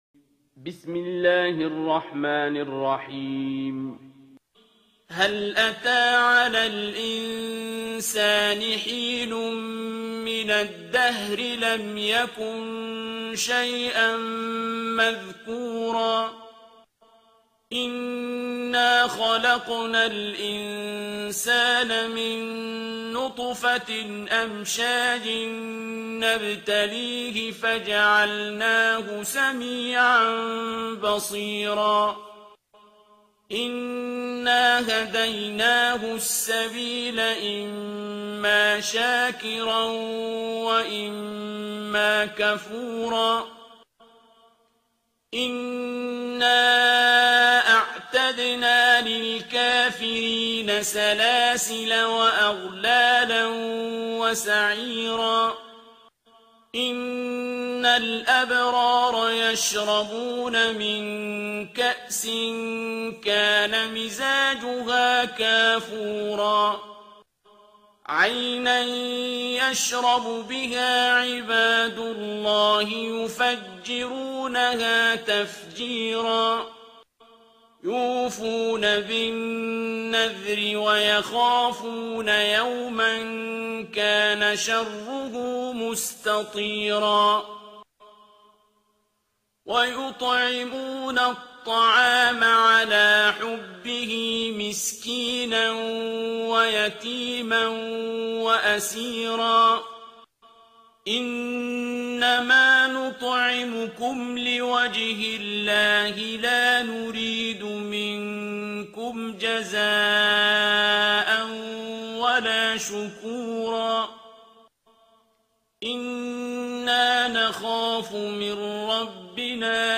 ترتیل سوره انسان با صدای عبدالباسط عبدالصمد